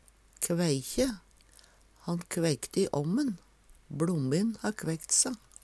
kveikje - Numedalsmål (en-US)